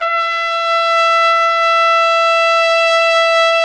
Index of /90_sSampleCDs/Roland L-CDX-03 Disk 2/BRS_Trumpet 1-4/BRS_Tp 3 Ambient